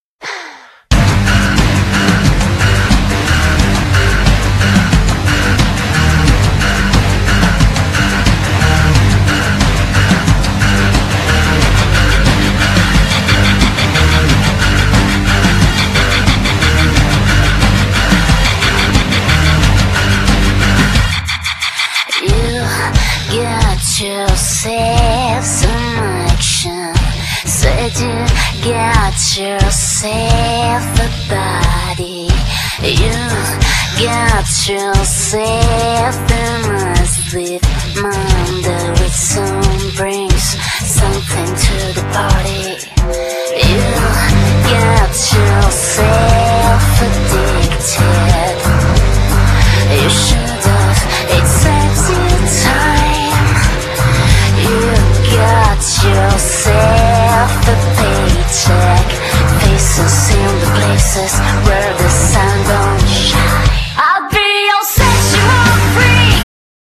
Genere : Rock elettro